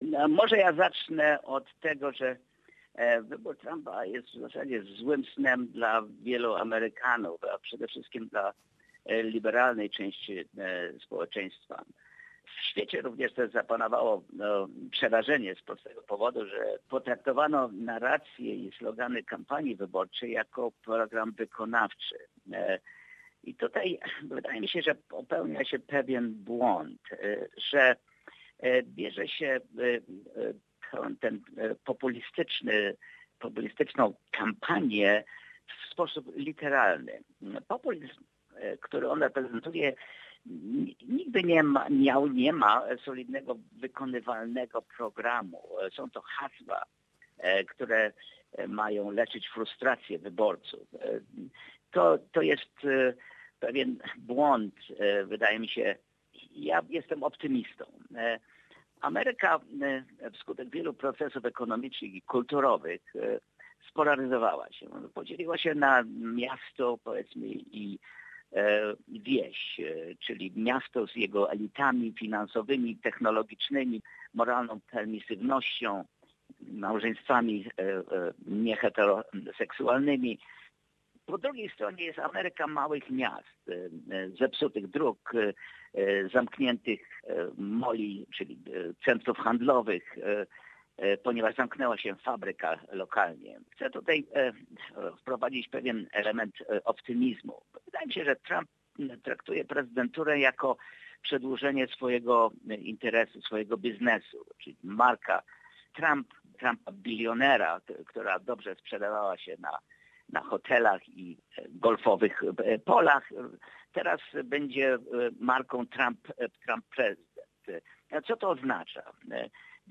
Jest to druga część rozmowy.